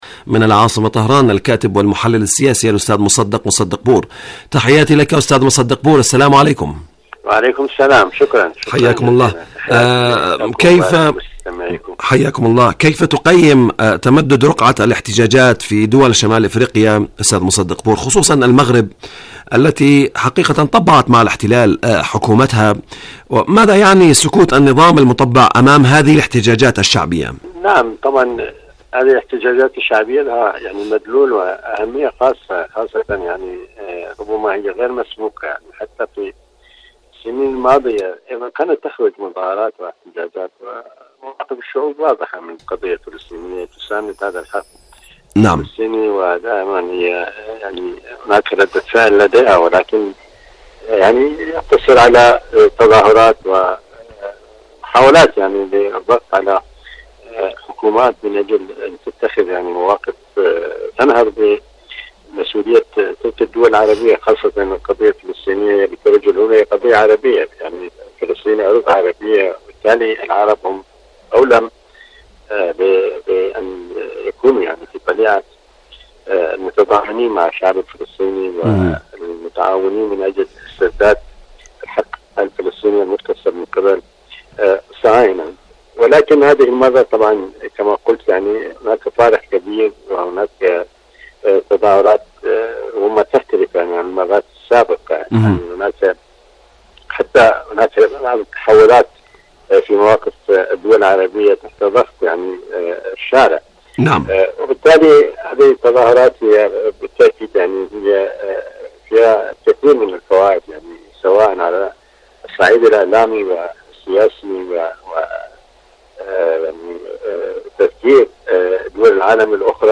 برنامج صدى المغرب العربي مقابلات إذاعية